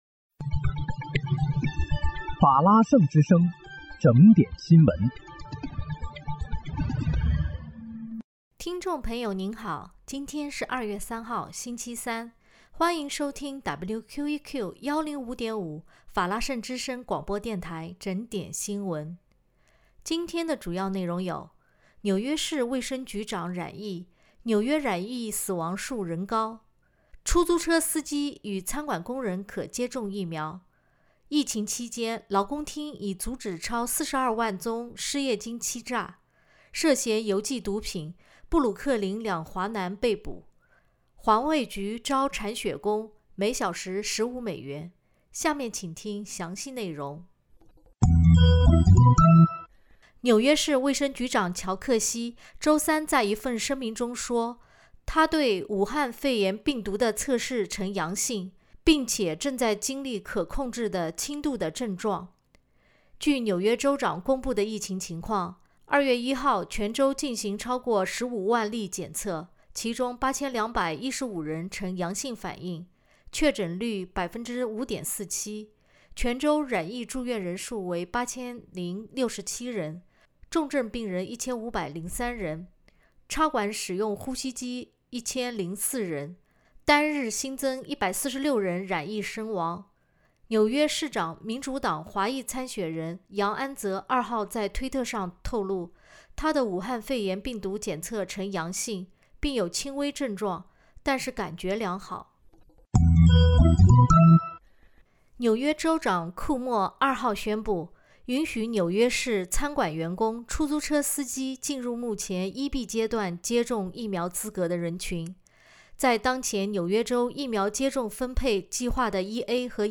2月3日（星期三）纽约整点新闻